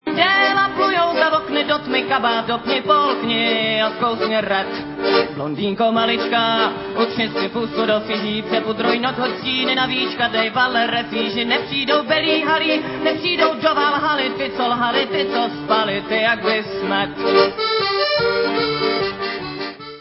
Záznam koncertu